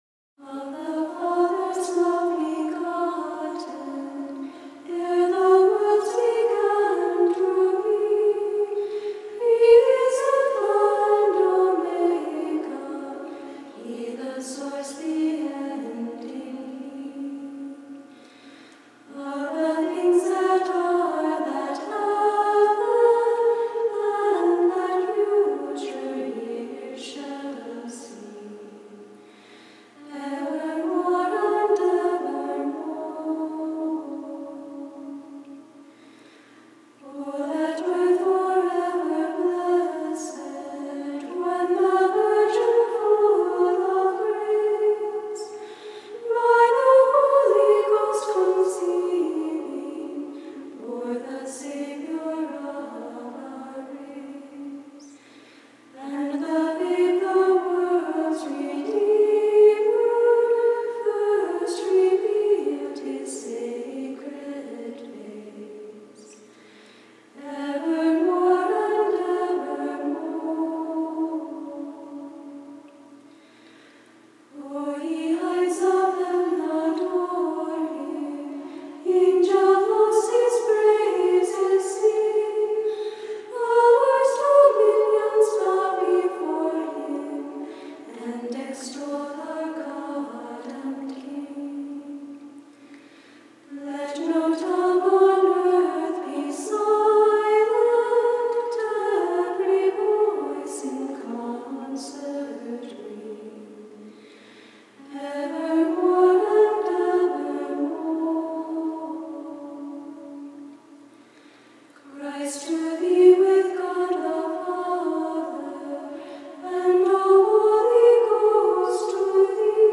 Christmas Hymn